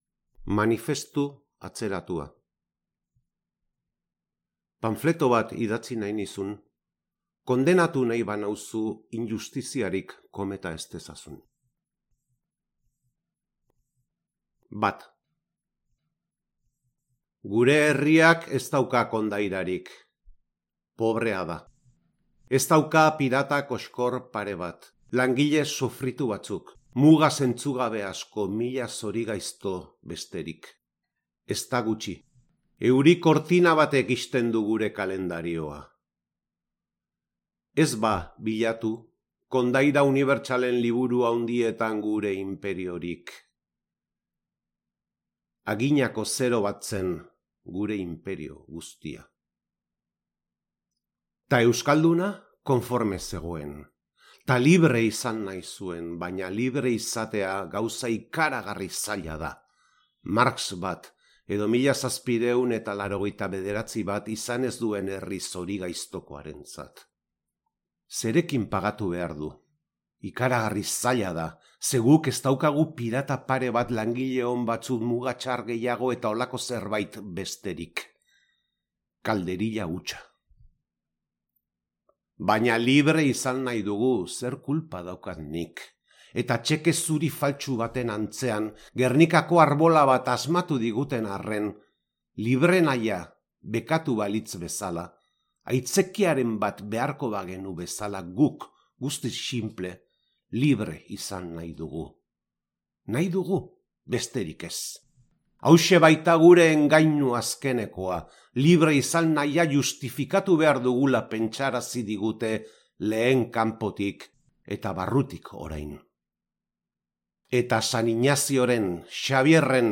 Berak idatzitako Manifestu atzeratua poema luze eta gogoangarriari ahotsa jarri dio